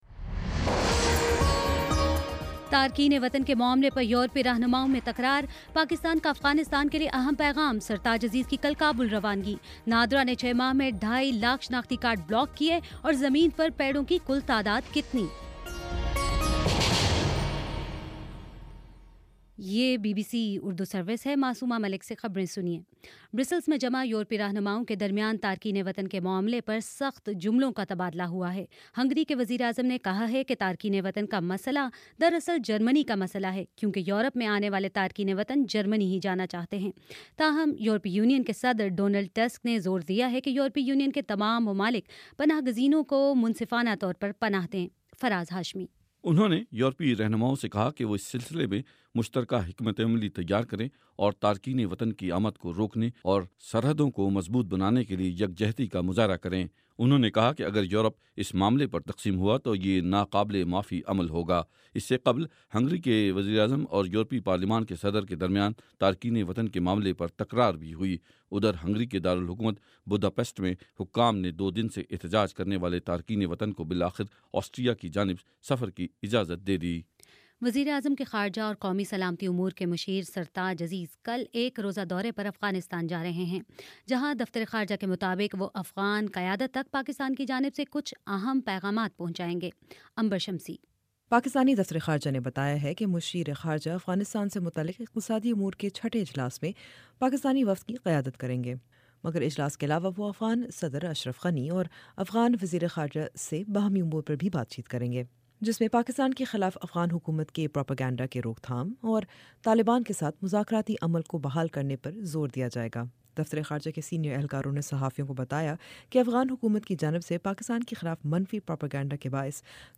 ستمبر 3: شام سات بجے کا نیوز بُلیٹن